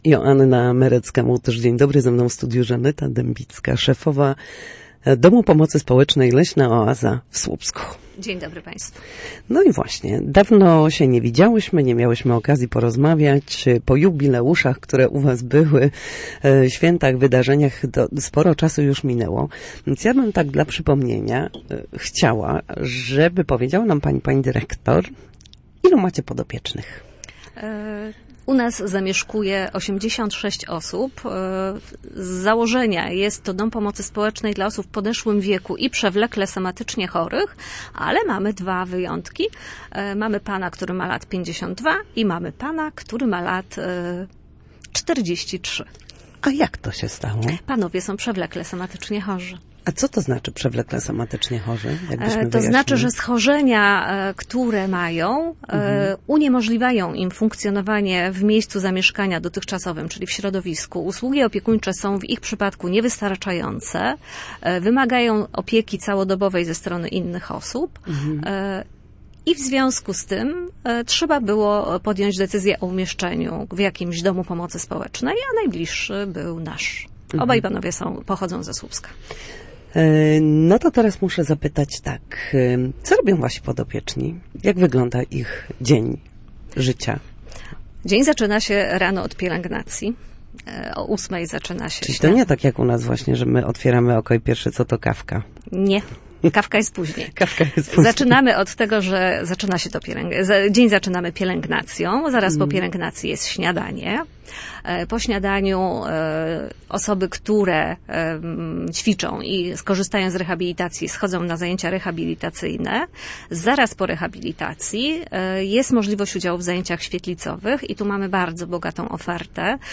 Na naszej antenie opowiadała o działaniach aktywizujących seniorów, byciu wrażliwym na ich potrzeby oraz o zbliżającym się plenerze malarskim.